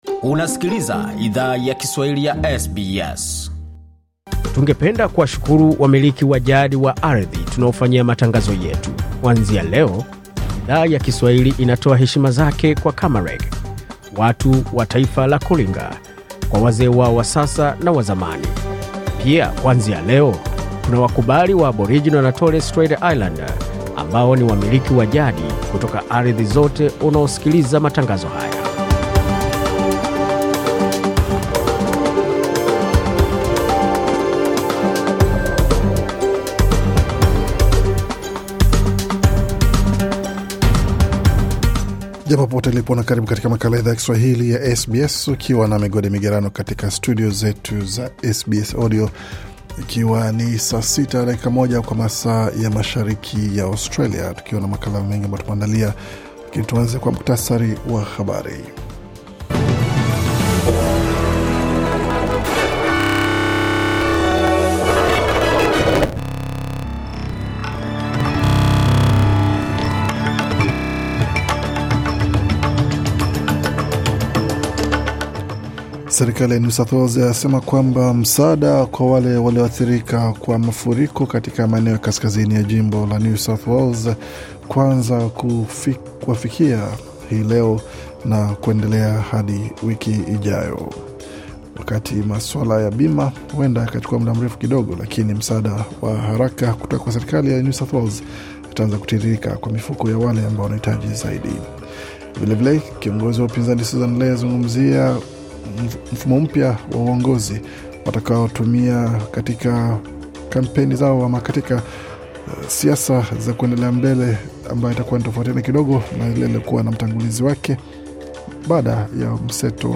Taarifa ya Habari 30 Mei 2025